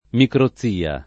microzia [ mikro ZZ& a ]